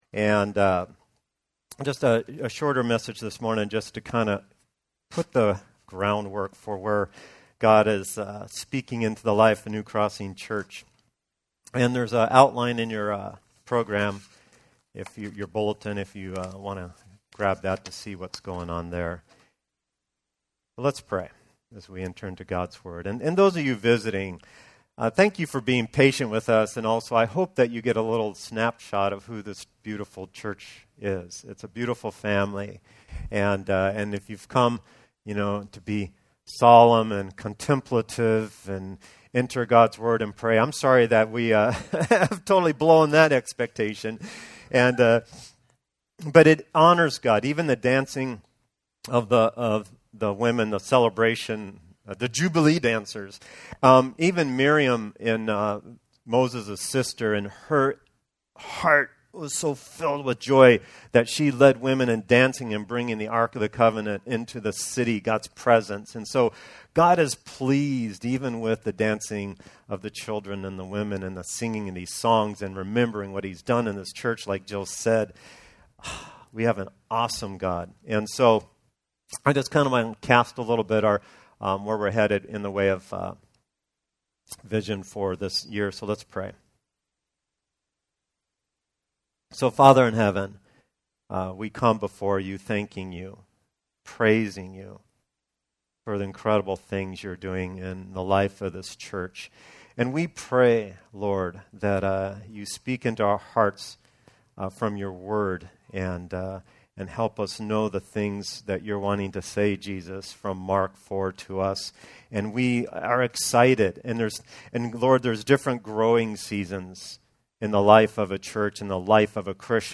Our Annual Celebration Service